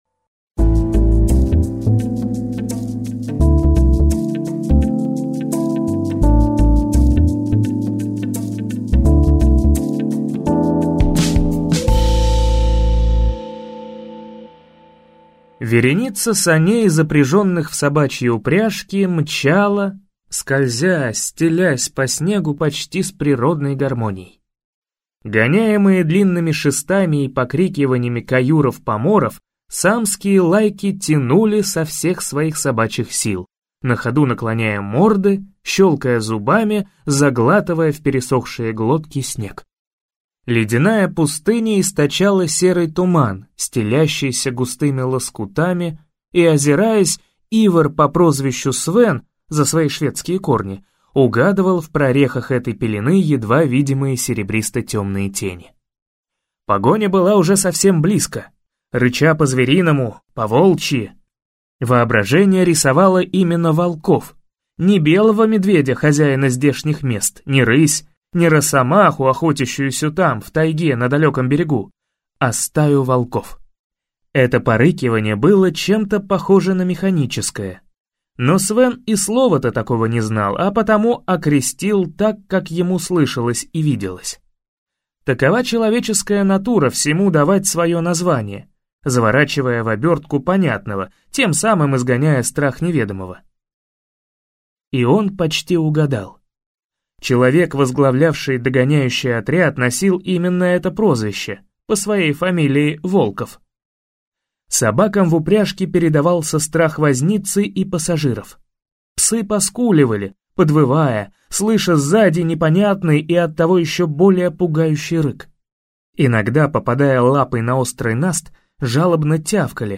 Аудиокнига Адмиралы Арктики | Библиотека аудиокниг
Прослушать и бесплатно скачать фрагмент аудиокниги